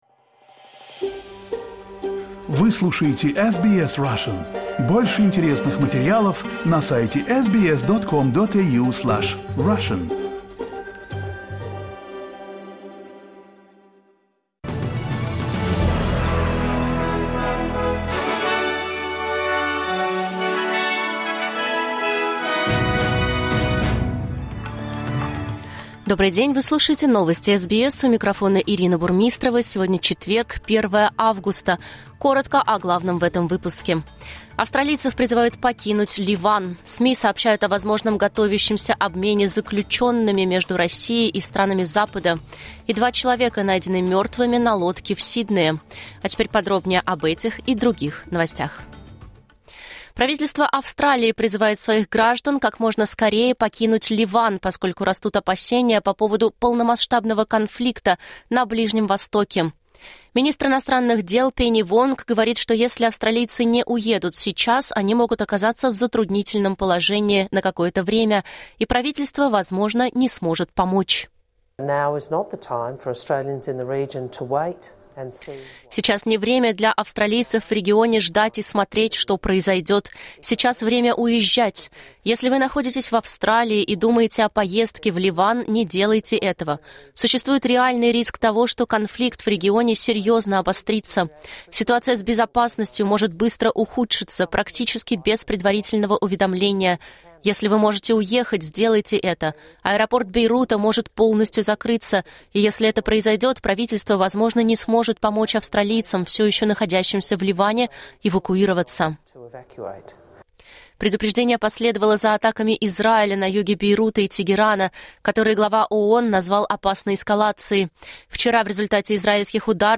Новости SBS на русском языке — 1.08.2024